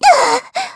Xerah-Vox_Damage_kr_03.wav